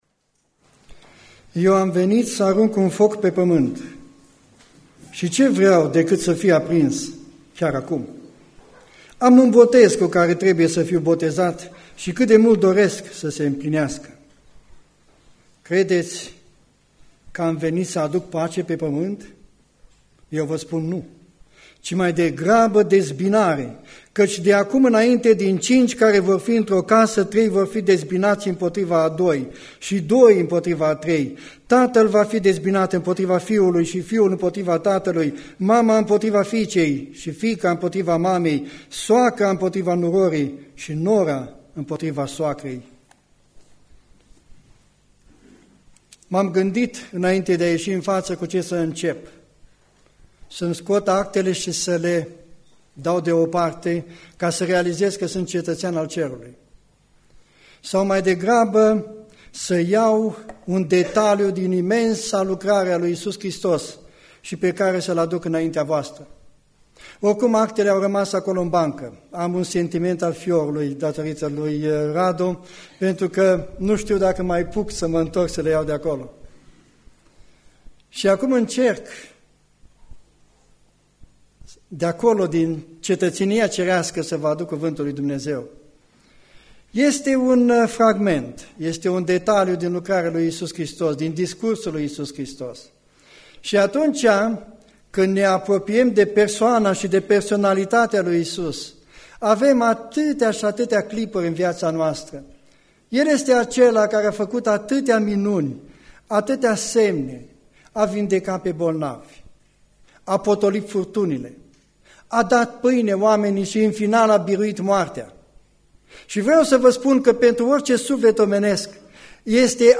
Predica Aplicatie - Ieremia 26-27